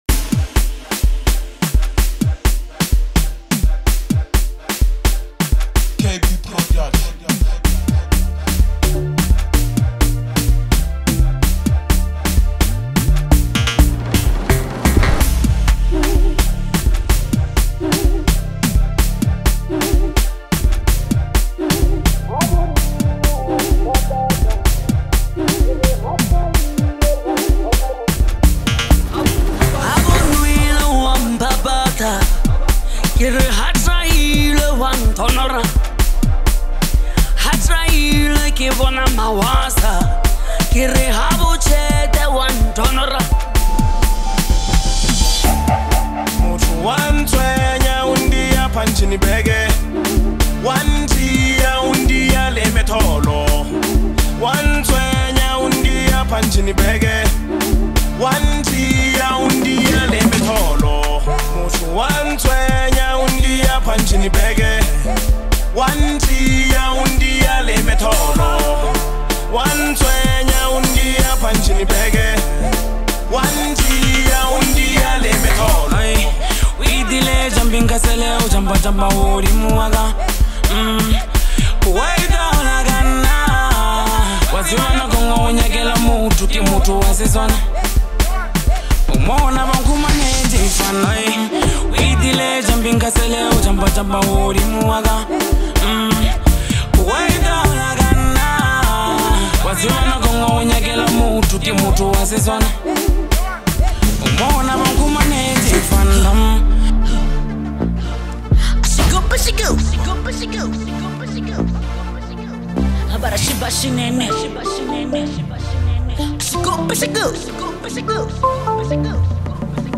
is a fiery track